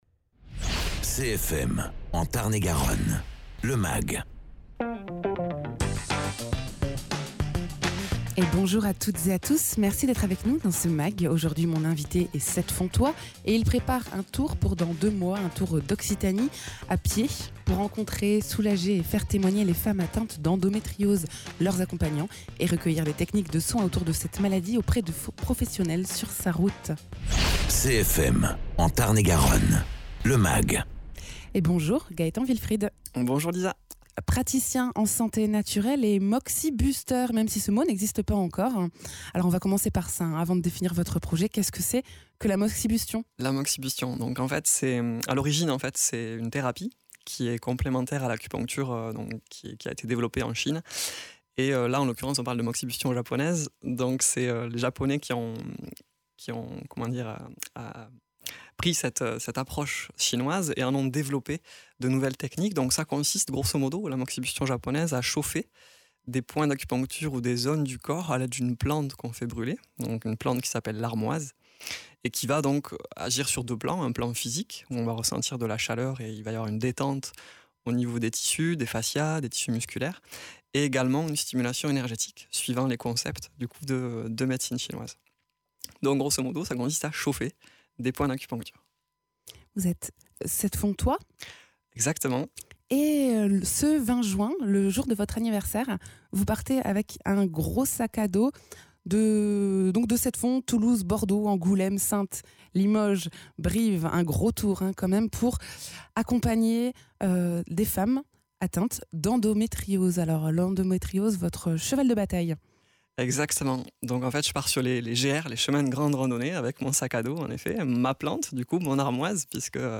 praticien en santé naturelle